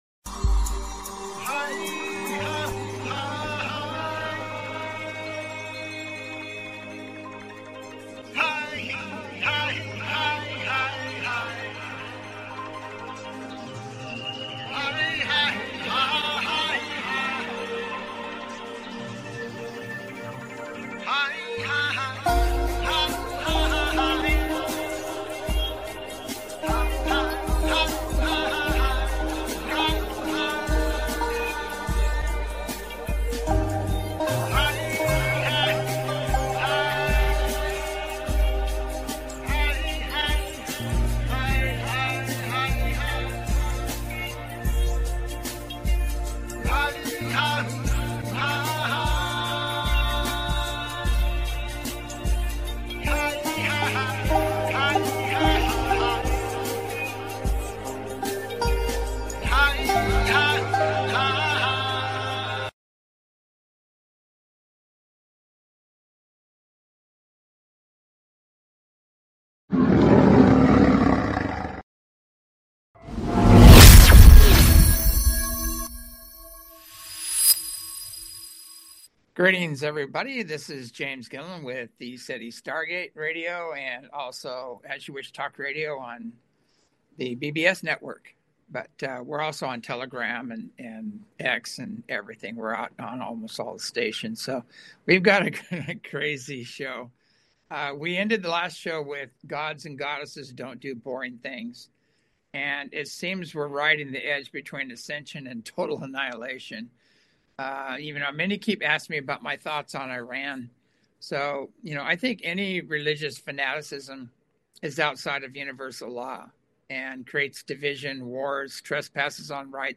Talk Show Episode, Audio Podcast, As You Wish Talk Radio and UFO and UAP, Iran Ice Riots, Questions and Answers on , show guests , about UFO and UAP,Iran Ice Riots,Questions and Answers, categorized as Earth & Space,News,Paranormal,UFOs,Philosophy,Politics & Government,Science,Spiritual,Theory & Conspiracy